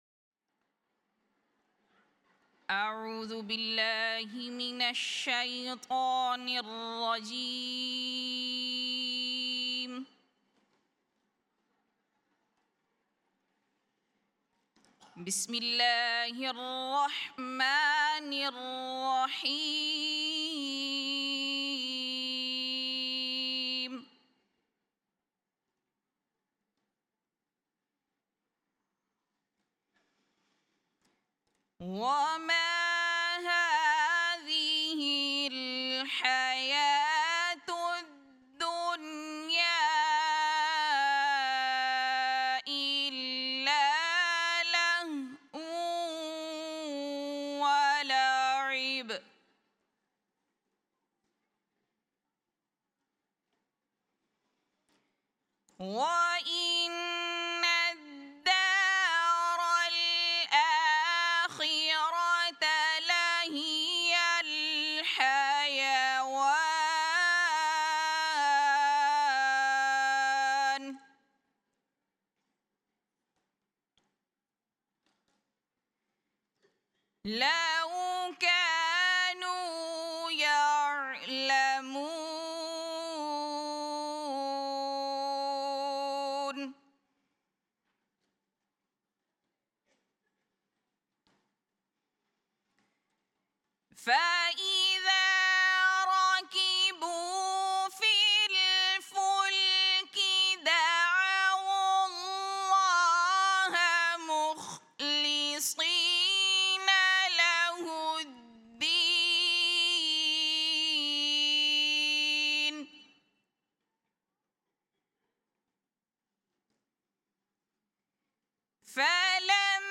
Jalsa Salana 2025: Ladies Session
Tilawat
2025+-+US+Jalsa+Lajna+Afternoon+Session+-+Tilawat.mp3